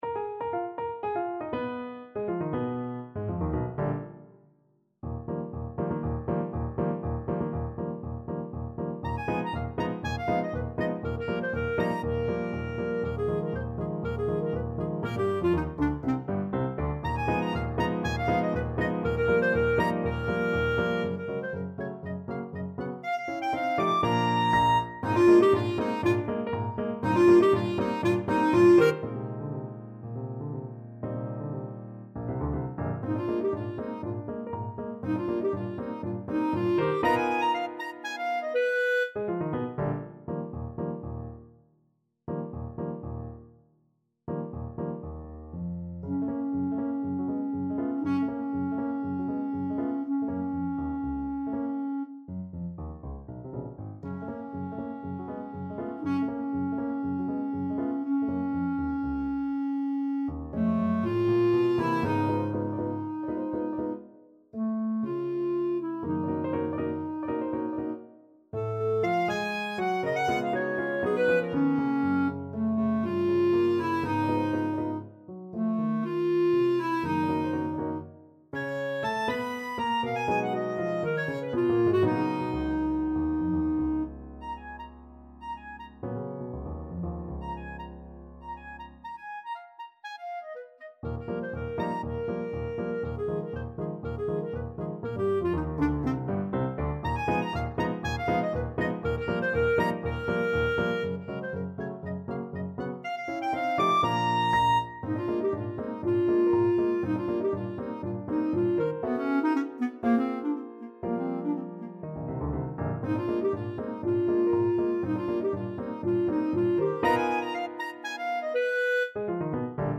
ClarinetPiano
Each quotation is followed with banjo imitations.
Allegro giusto (View more music marked Allegro)
2/4 (View more 2/4 Music)
Clarinet  (View more Intermediate Clarinet Music)
Classical (View more Classical Clarinet Music)